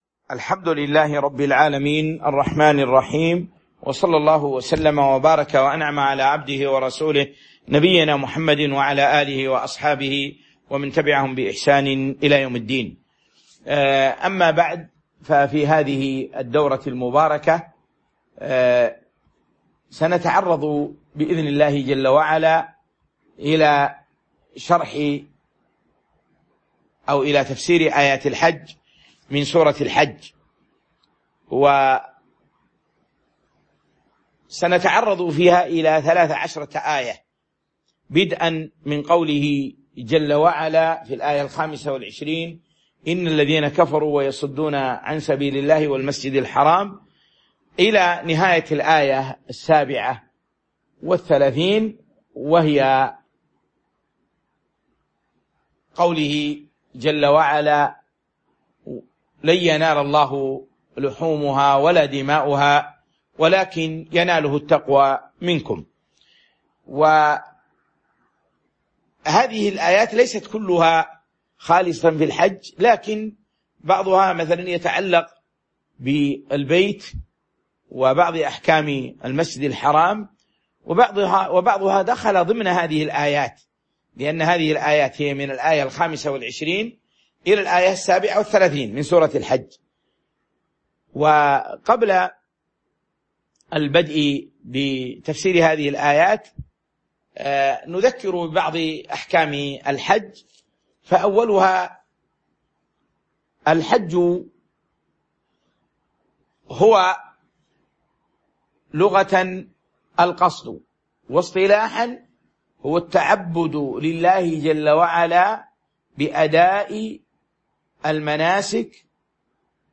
تاريخ النشر ٢١ ذو القعدة ١٤٤٢ هـ المكان: المسجد النبوي الشيخ